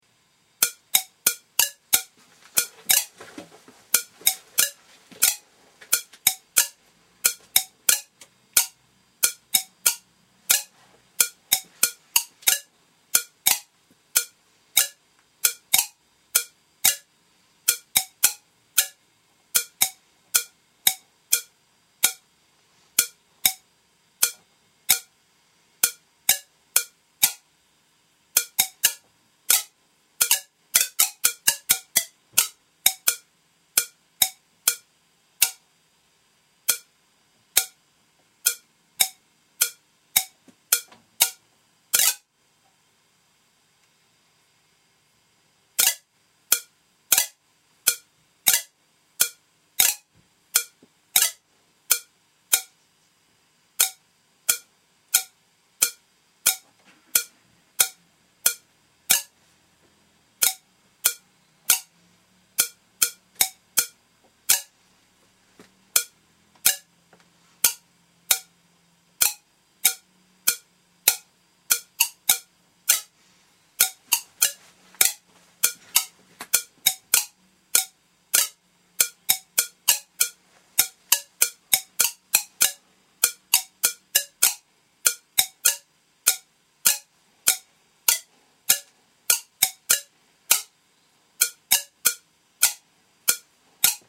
Simply switching it on and off at regular intervals imposes a rythmic beat to the sound, as sparks will only occur when power is on. Adjusting the on/off ratio controls how strictly the spark timing follows the beat - with a short on-time, the sparks happen pretty much together, usually several at a time.
(1.4MB) In this sample, the only manual intervention was occasional adjustment of the pulse rate and on/of ratio.
Acrylic tube was used so the sparks were nicely visible, and a set of tubes were cut to resonate at musical notes A (440hz) upwards.